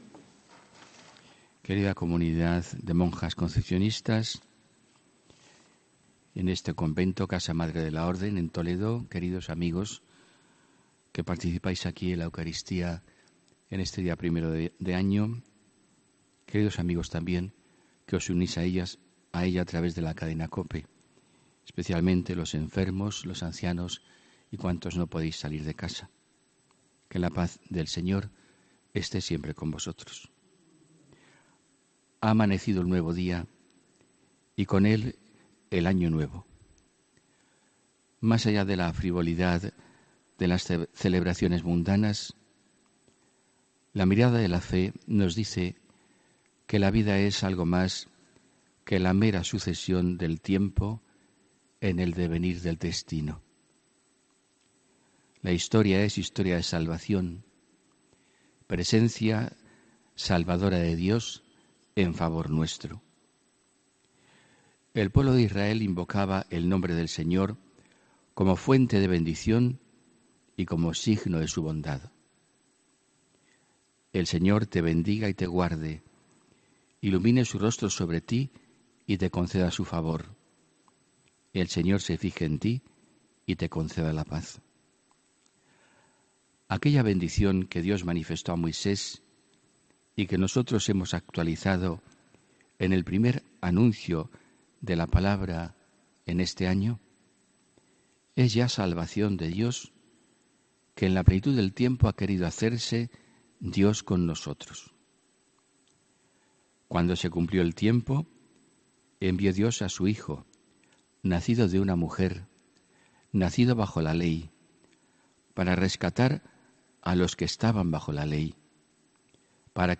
HOMILÍA 1 ENERO 2019